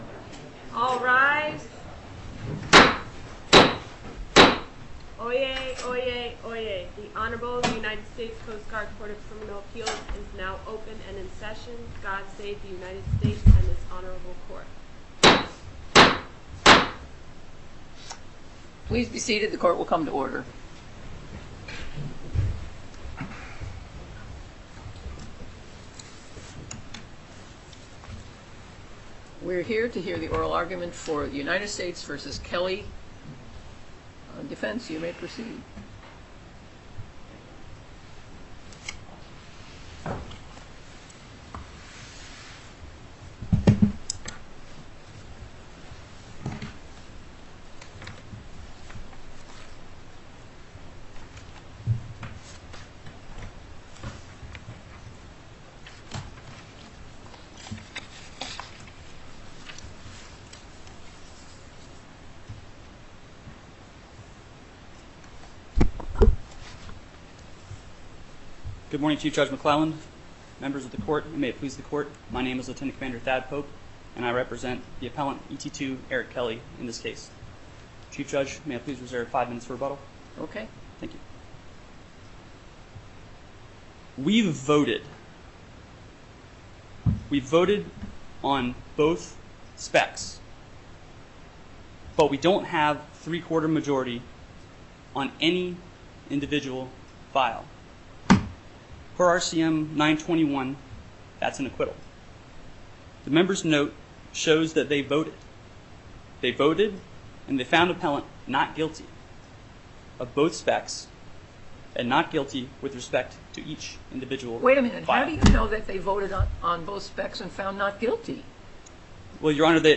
Oral Arguments